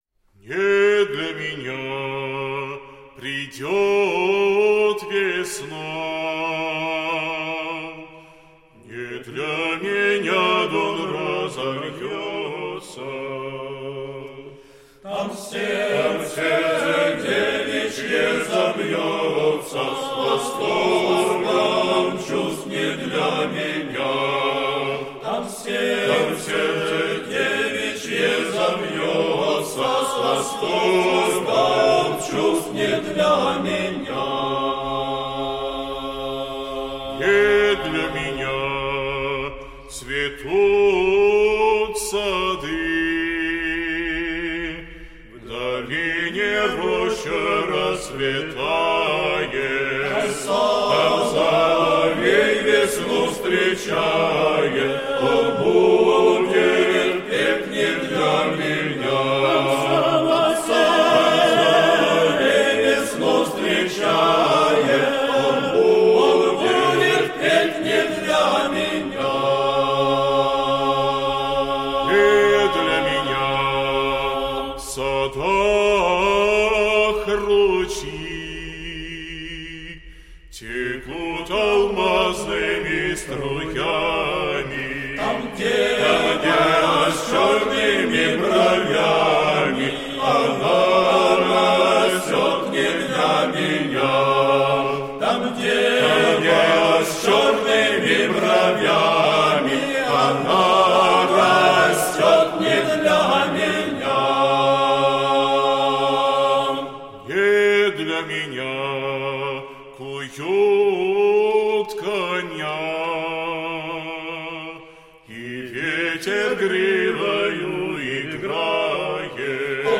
Тенора:
Басы:
Донская